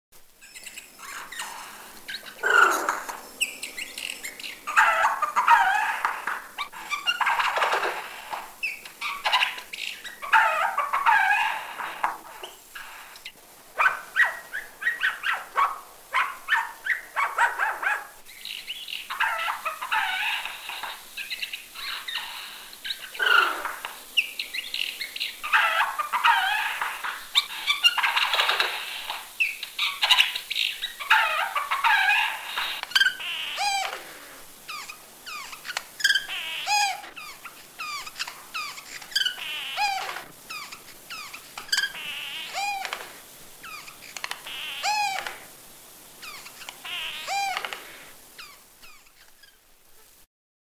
Звук голоса пересмешника нельзя назвать уникальным, ведь он может копировать и подражать голосам других птиц и даже человеческому голосу.
Этих певчих птиц часто приручают и держат в клетках-вольерах дома, потому как звуки голоса пересмешника очень красивы и мелодичны, тут они составляют конкуренцию канарейкам, чижам, и, конечно же, соловьям.
Голос сойки-пересмешника:
golos-sojki-peresmeshnika.mp3